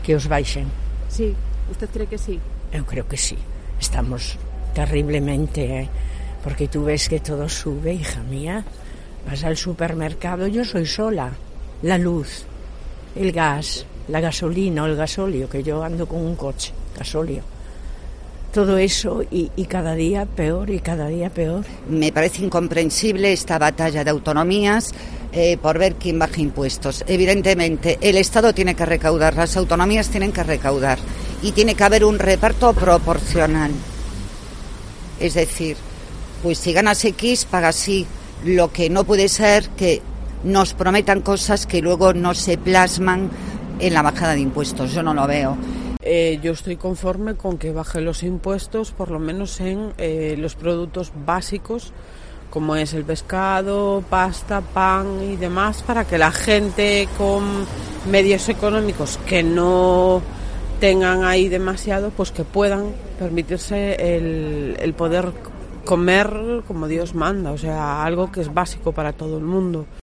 ¿Subir o bajar impuestos en Galicia? preguntamos en la calle